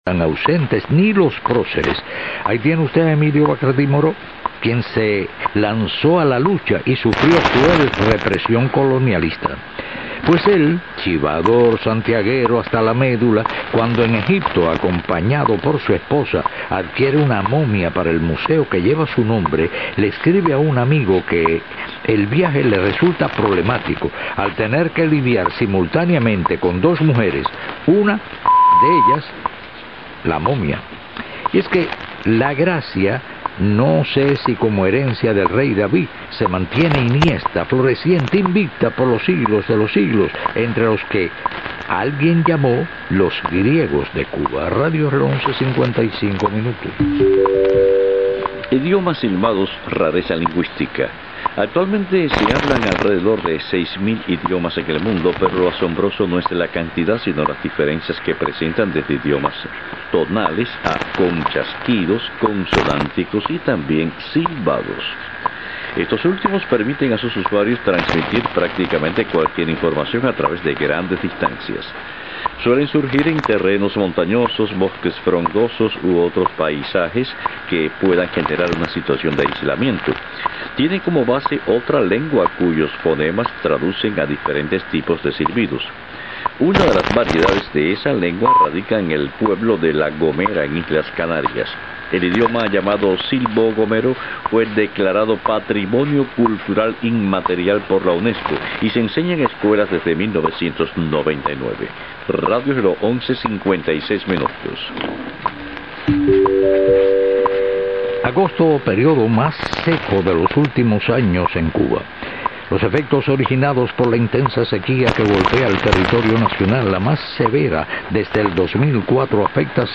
The recording, in Spanish, is a typical Radio Reloj broadcast with two announcers alternately reading news bulletins accompanied by time signals. The announcers identify the station and verbally give the local time each minute.
In addition to the verbal station identification, each minute either the letters RR in morse code (using 1800 Hz tones) are transmitted or five-note chimes (D4, G4, B4, D5, B4) are played. The chimes sound like those of a dinner chime or even some door bells and are reminiscent of the U.S. National Broadcasting Company (NBC) chimes.
In this recording, we can also hear at some minutes pairs of tones being played going up and down the scale as news headlines are read.
Different tones identify each second, minute, and five-minute epochs. Based on measurements, each second is marked with a "seconds tick" consisting 10 cycles of a 1000 Hz tone (0.01 seconds duration).
Every 5 minutes, the marker is extended to 672 cycles of a 1000 Hz tone (0.672 seconds duration).